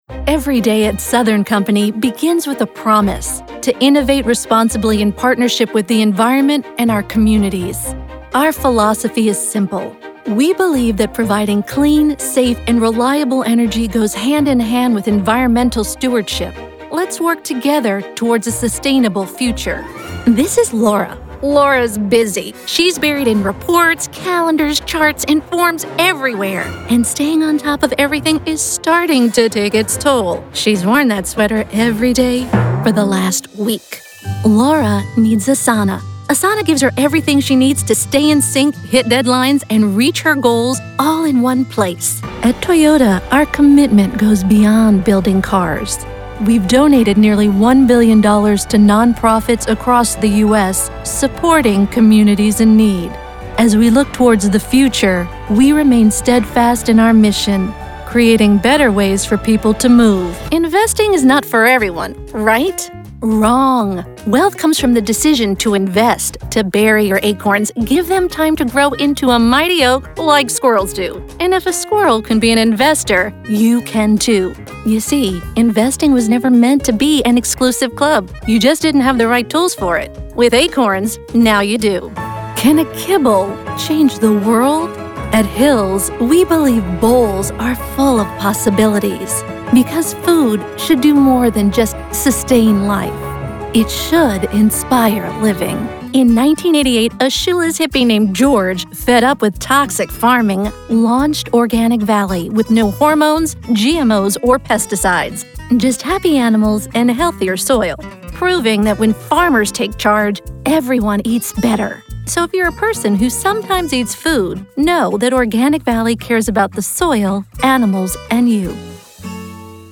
English (North American)
southern
Young Adult
Middle Aged
I can deliver warm and sincere, intelligent and authoritative, natural and bright and all the combinations in between.